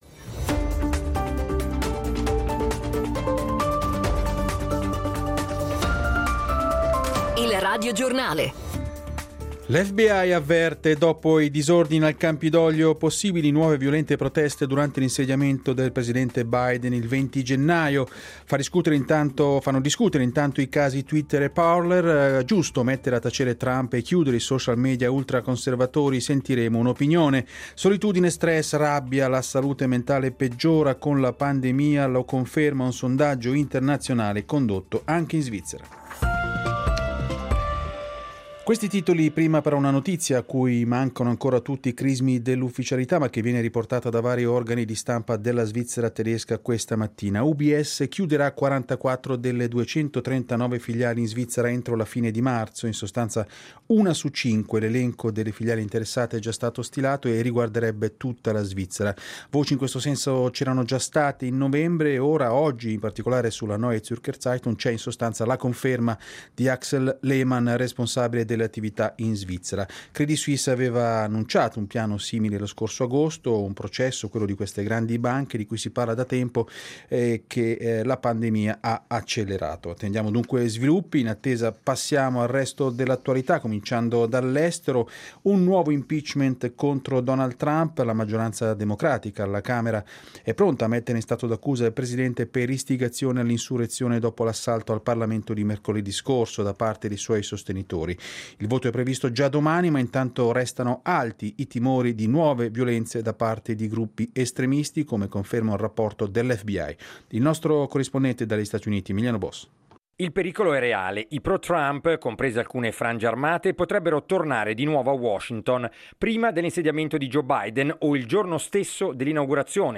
Radiogiornale